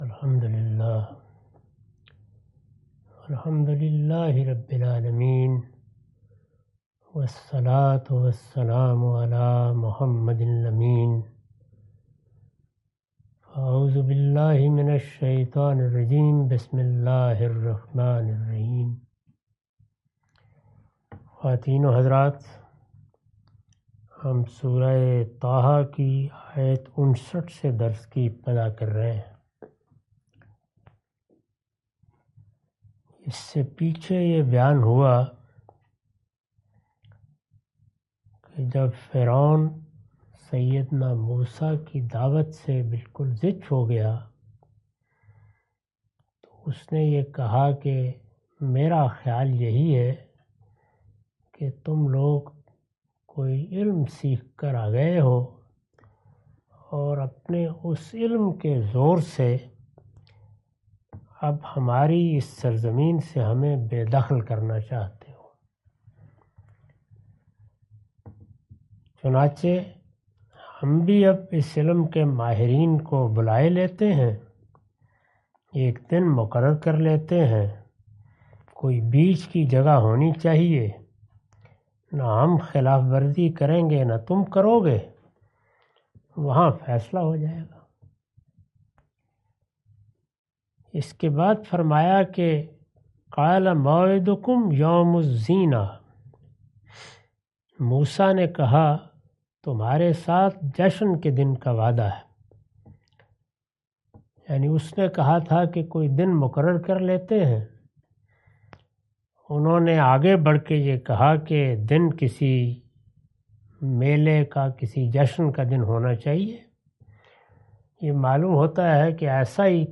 Surah Taha A lecture of Tafseer-ul-Quran – Al-Bayan by Javed Ahmad Ghamidi. Commentary and explanation of verses 59-62.